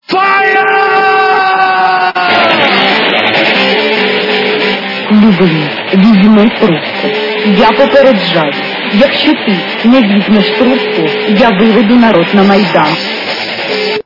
Люди фразы